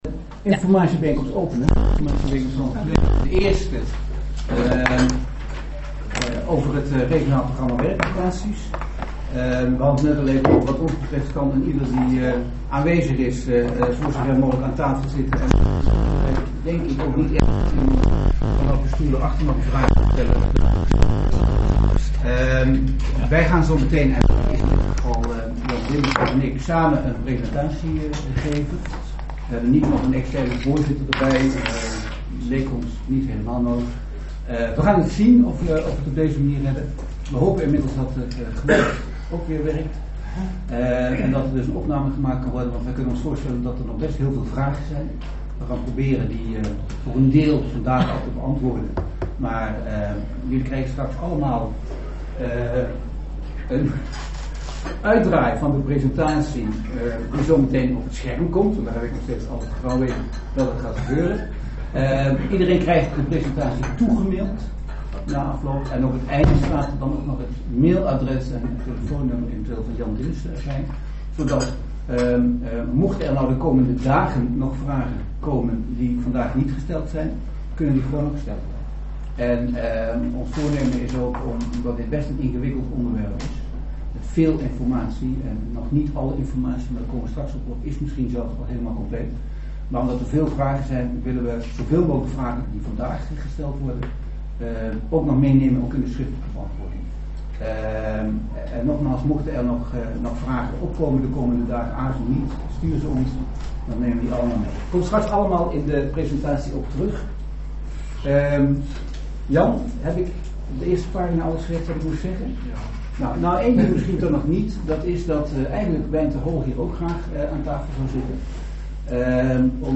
Elster Toren S02, gemeentehuis Elst